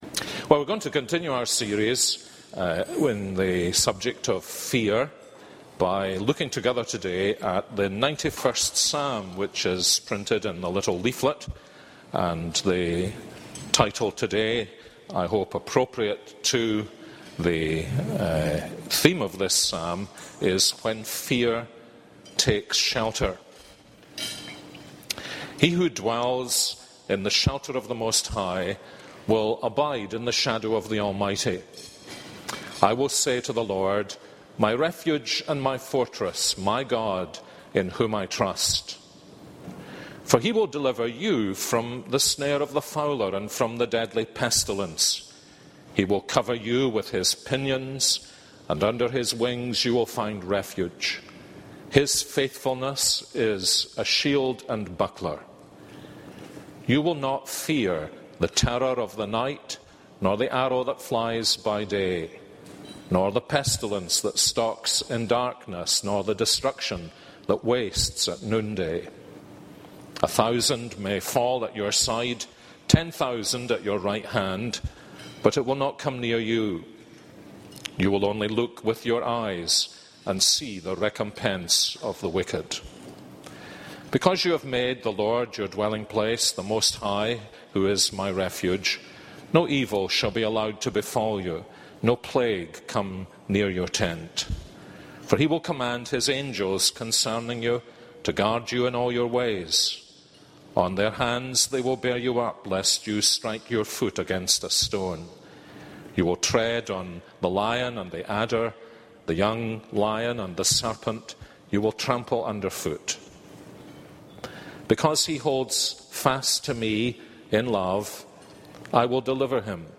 This is a sermon on Psalm 91.